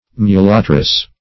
Mulattress \Mu*lat"tress\, n.